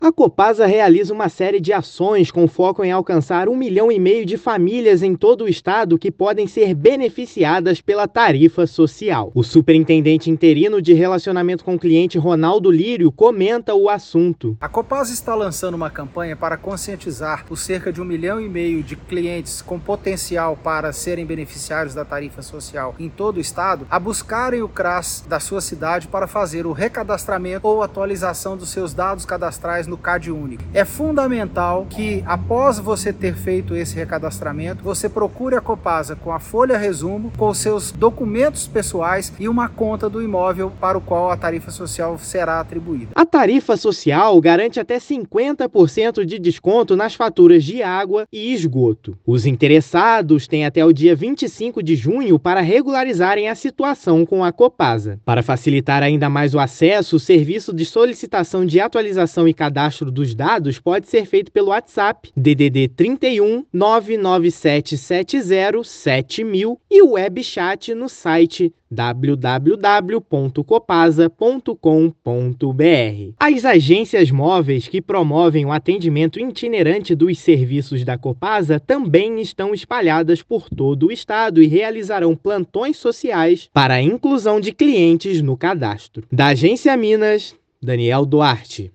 Benefício pode promover redução de até 50% nas contas de água/esgoto para famílias de baixa renda em Minas. Ouça a matéria de rádio: